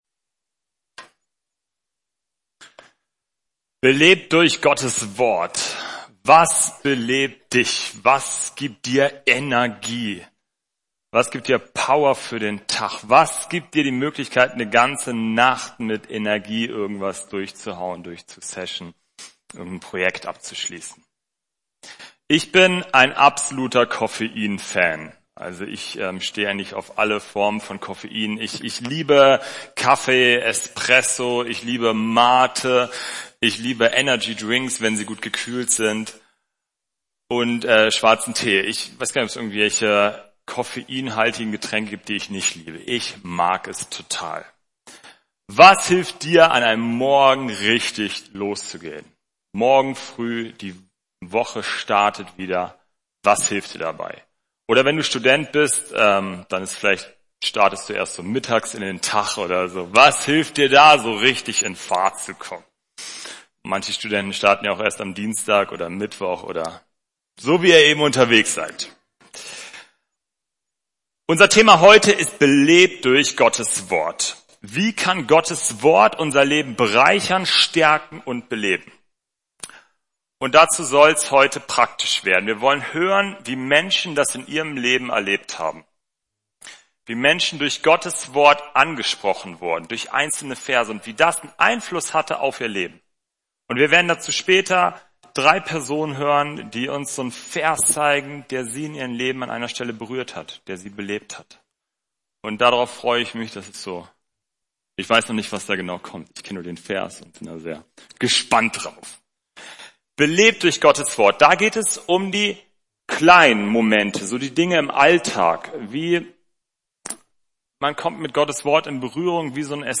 Freiraum Gottesdienst